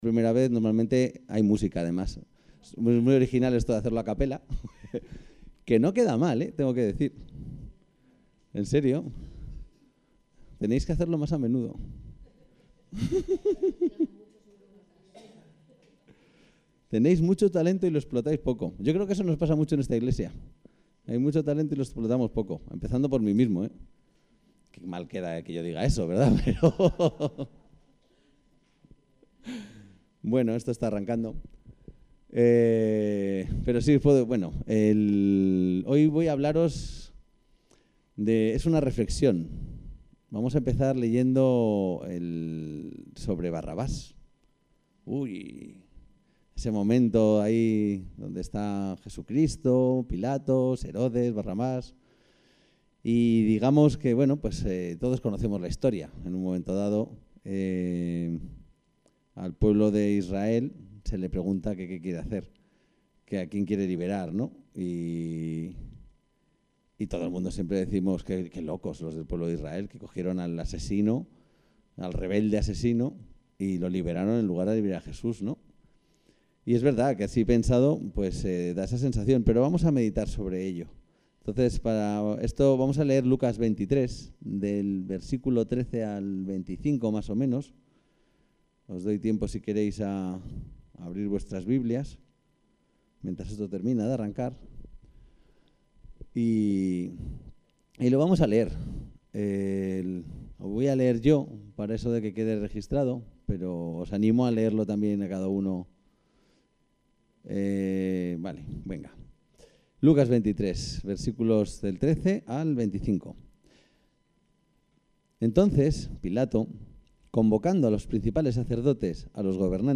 El texto de la predicación se encuentra aquí: Eleccion